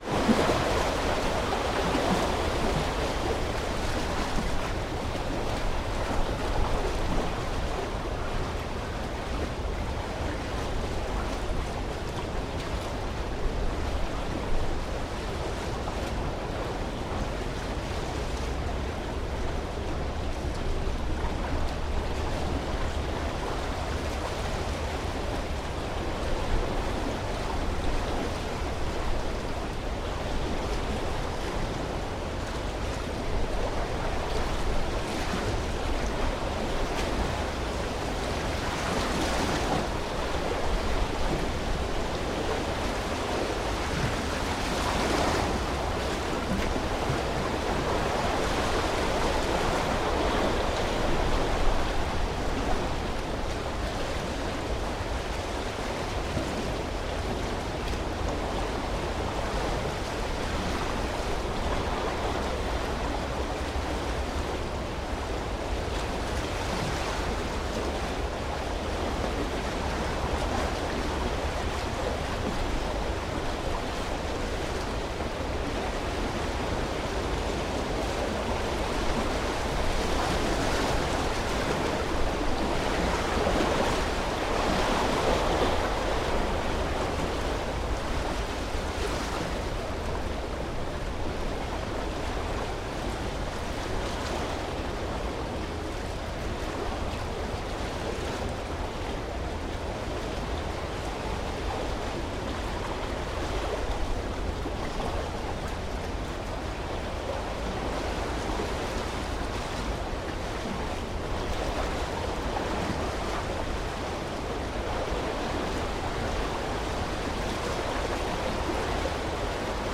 Звуки моря, волн
Шум волн у причала